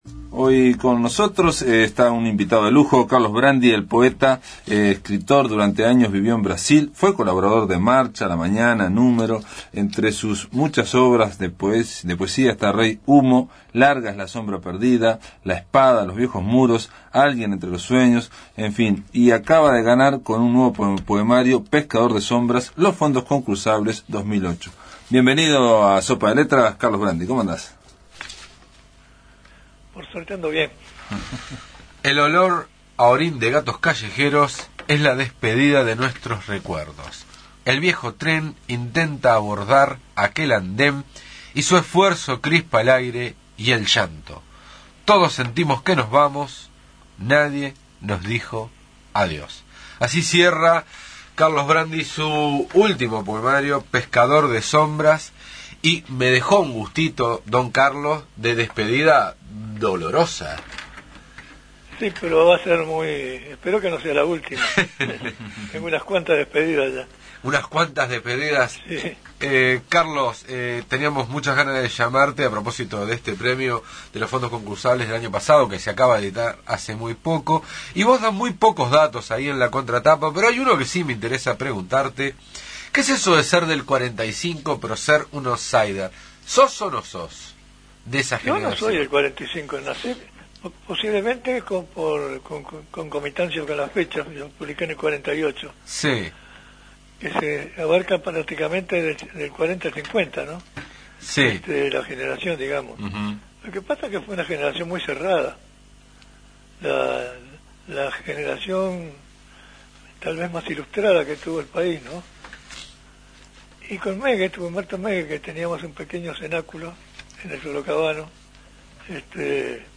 Material de archivo